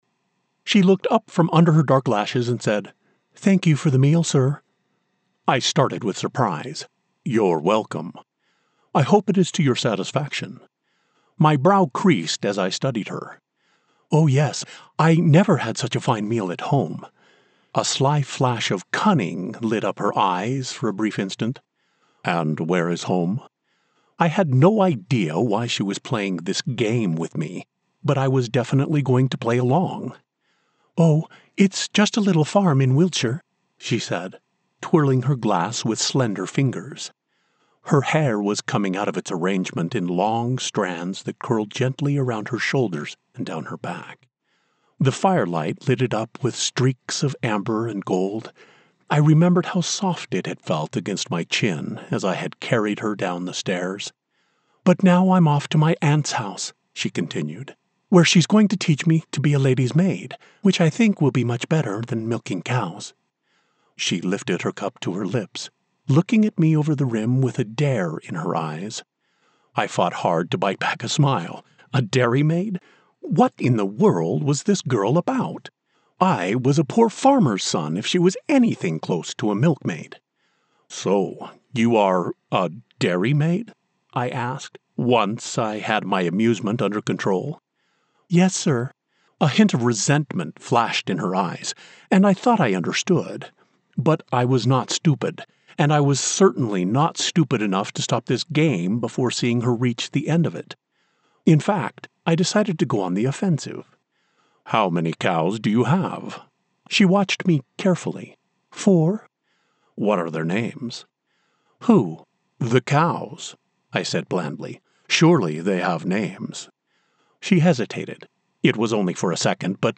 Rich • Robust • Trustworthy
A warm embrace of fatherly wisdom.
Romance • MF Dialogue
General American